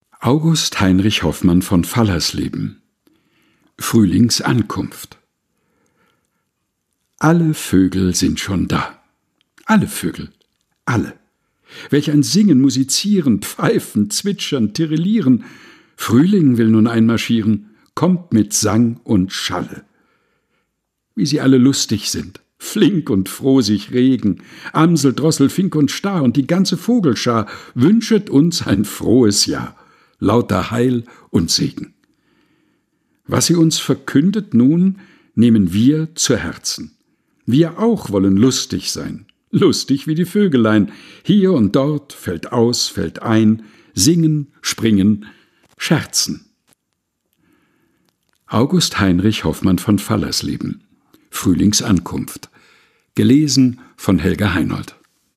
Texte zum Mutmachen und Nachdenken - vorgelesen